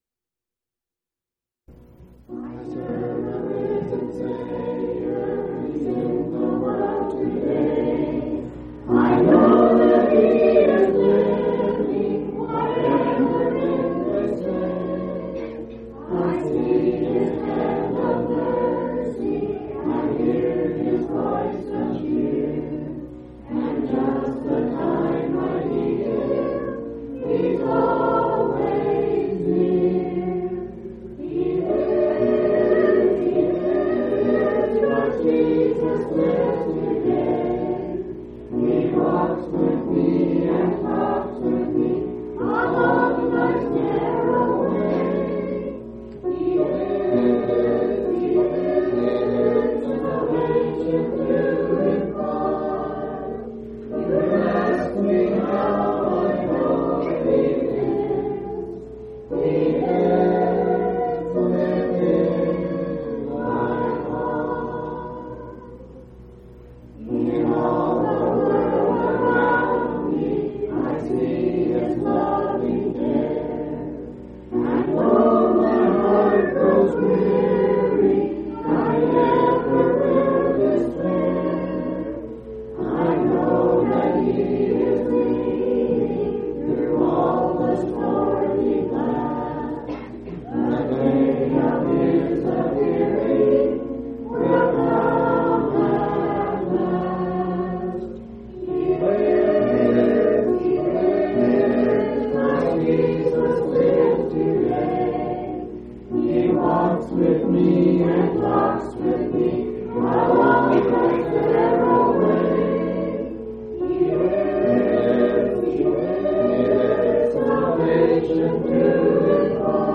6/15/1996 Location: Colorado Reunion Event